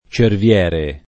©ervL$re] o cerviero [©ervL$ro] s. m. («lince») — anche lupo cerviere o cerviero — solo cerviero come agg. (poet. «lìnceo»): pronta vista, occhio cerviero [